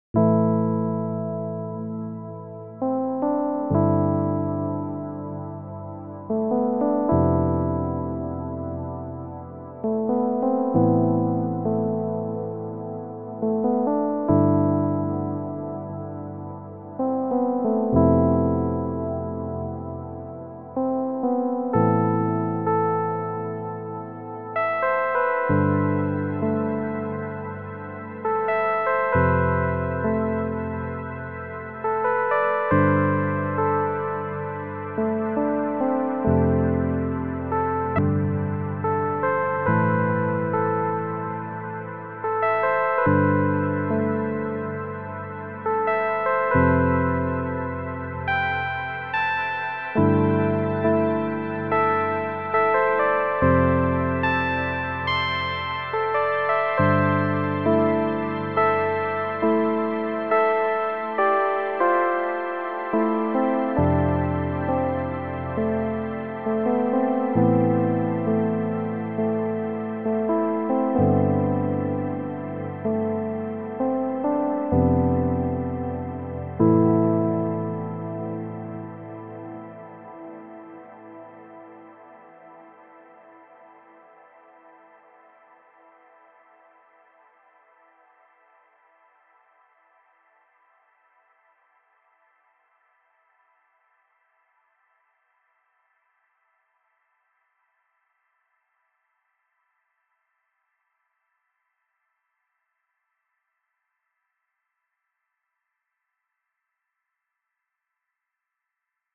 Just made an Electronic Piano preset with Chiral with super long space setting and improvised with it.
Sometimes some notes get silenced after the initial attack. It can clearly be heard on this recording on one note.
Seems to be the voice stealing.